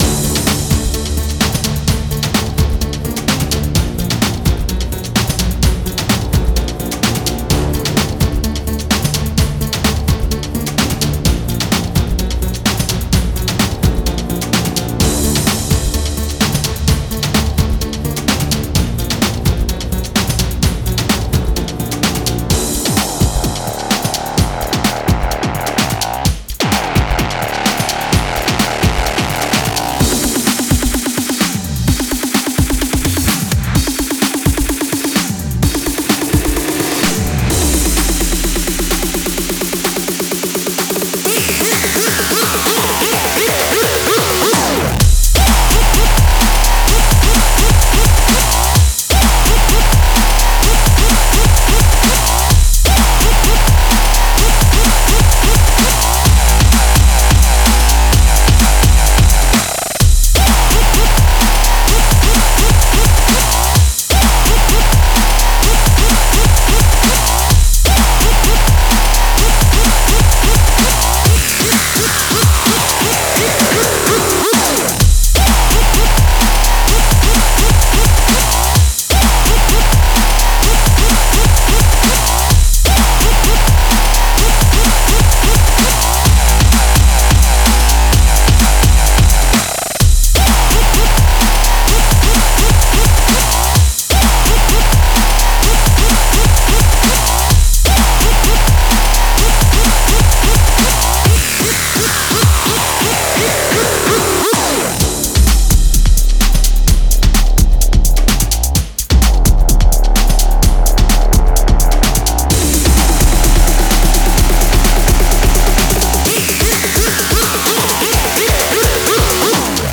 Rapper / Lyricist needed for Prodigy style track. See description for backing track